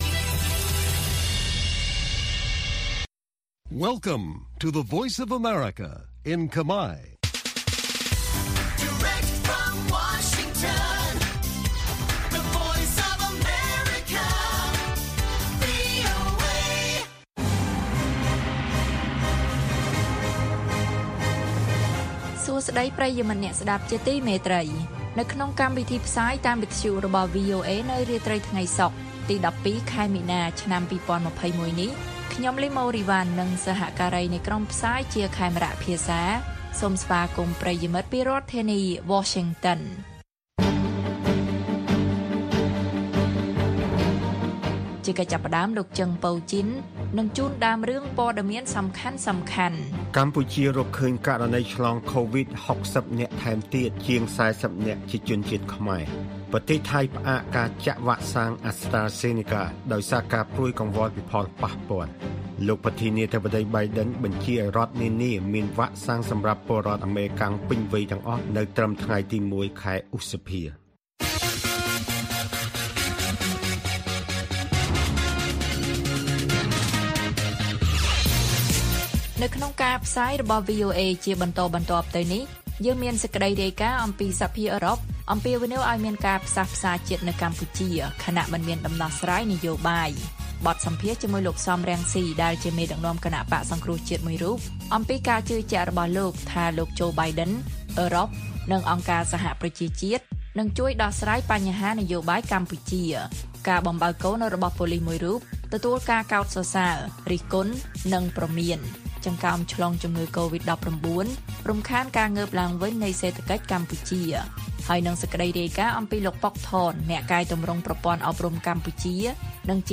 បទសម្ភាសន៍ VOA៖ លោក សម រង្ស៊ី ជឿថាលោក បៃដិន អឺរ៉ុបនិងអង្គការសហប្រជាជាតិ នឹងជួយដោះស្រាយនយោបាយកម្ពុជា។